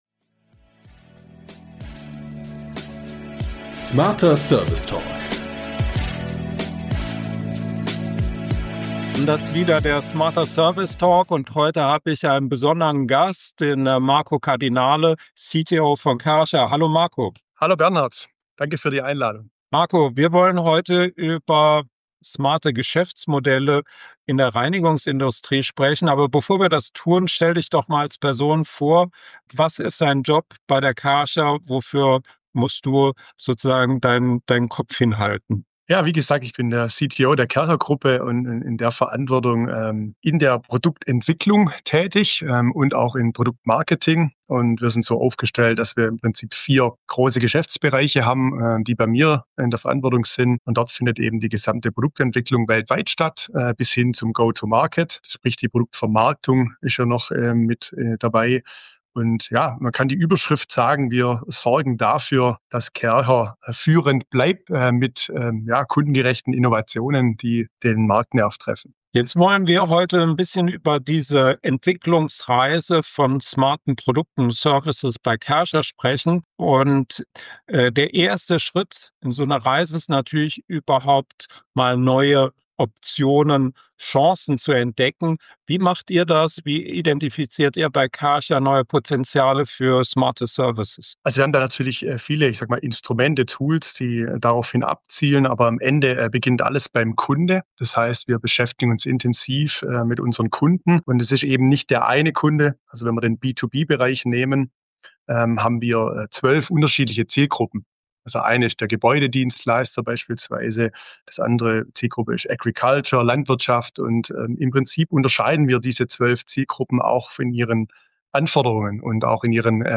Ein Gespräch über Fachkräftemangel, Sensorik, Partnerschaften, Mut und die Kunst, auch im Maschinenbau neue Wege zu gehen.